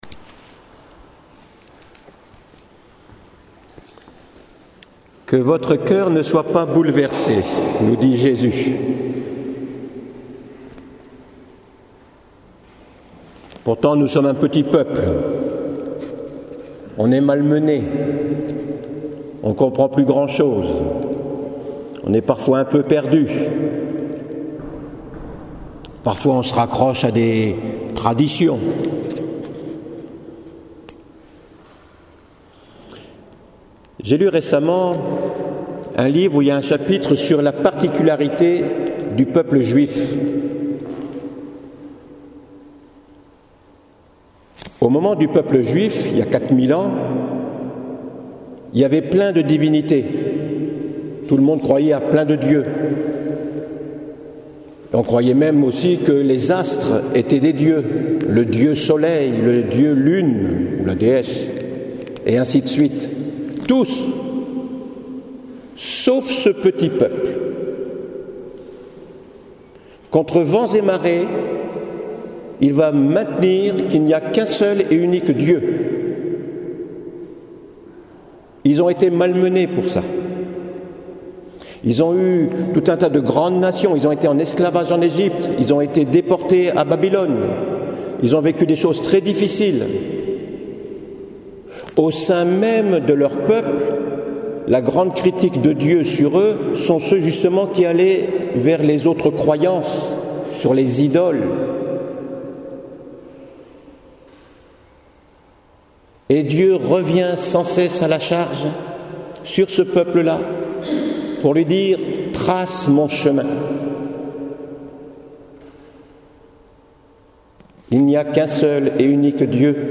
Voici l’homélie en version audio de ce dimanche => Entrer dans le mystère de Dieu